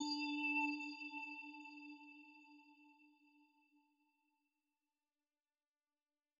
3rdbeat_success_bell.wav